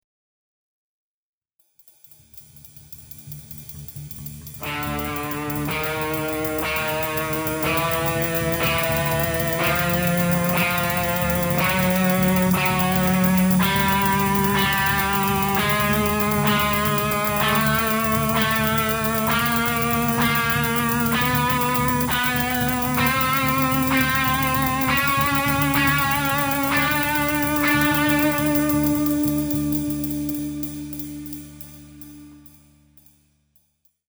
First play and sing each note simultaneously, and then, as shown in FIGURE 1d, sing each successive pitch before you play it.